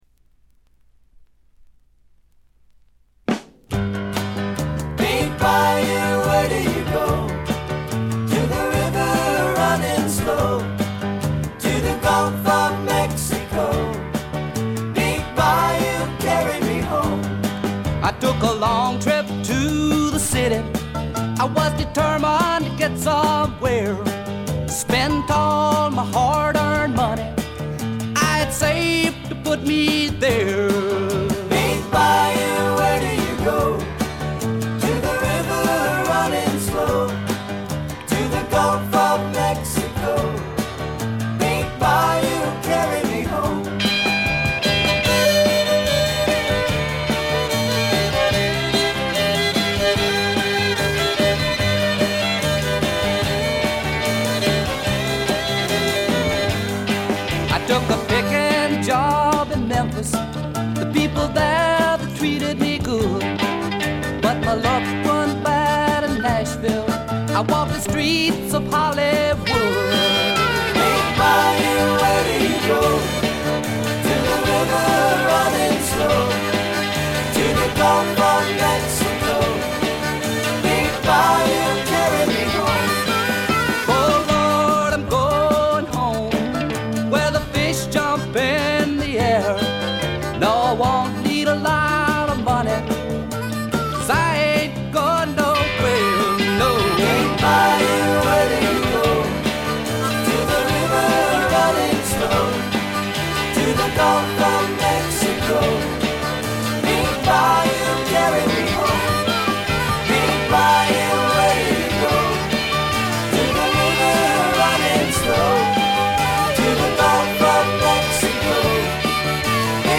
ところどころで軽微なチリプチ。散発的なプツ音少し。
試聴曲は現品からの取り込み音源です。